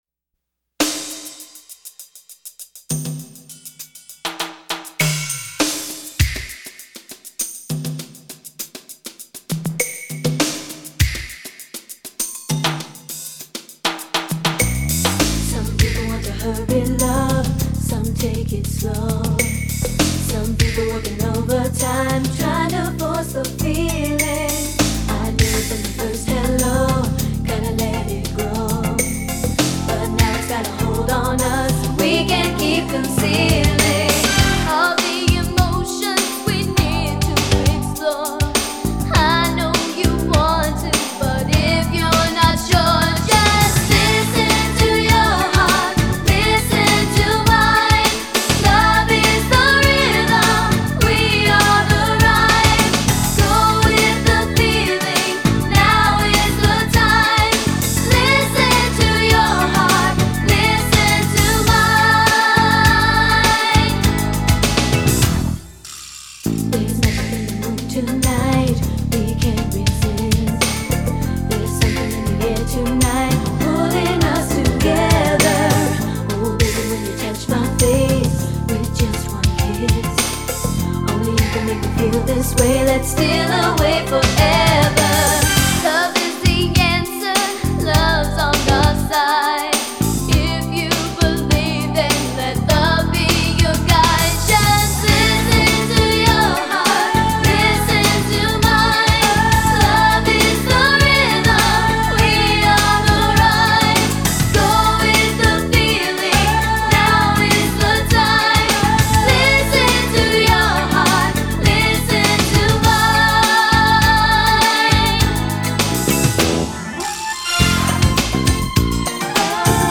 Genre: Pop-Rock.